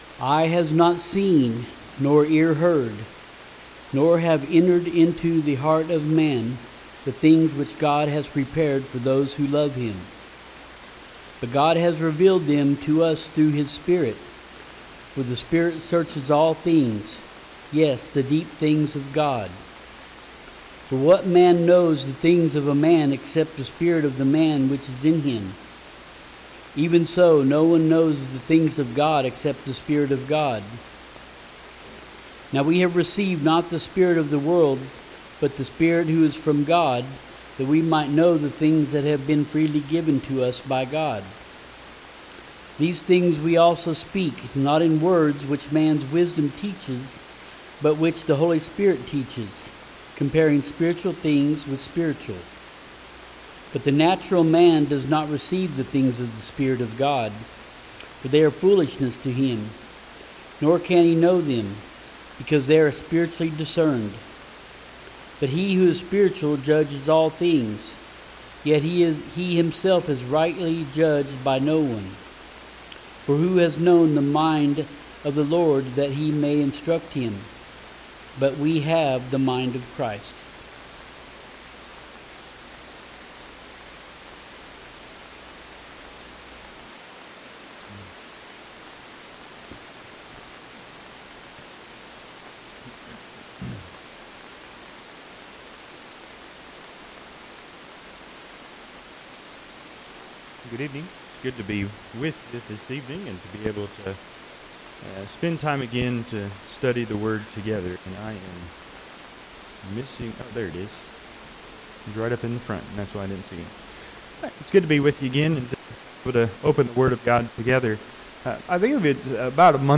1 Corinthians 2:9-16 Service Type: Sunday PM Topics